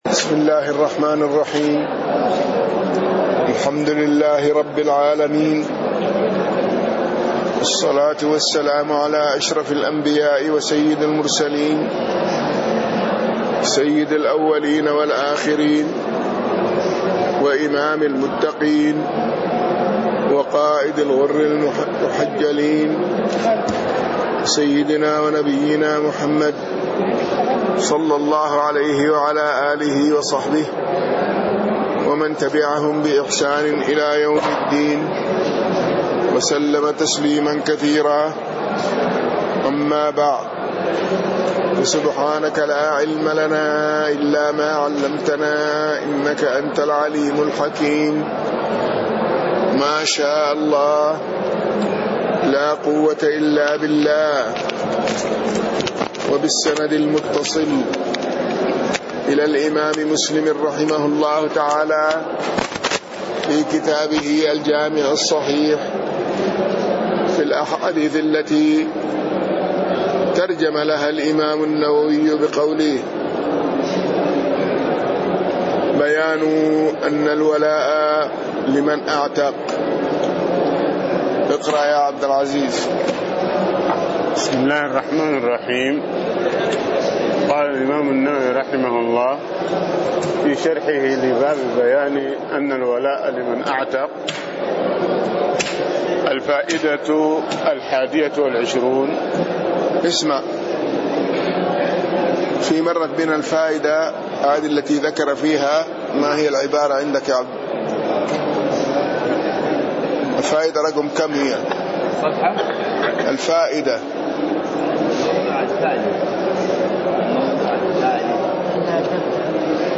تاريخ النشر ١٤ شوال ١٤٣٤ هـ المكان: المسجد النبوي الشيخ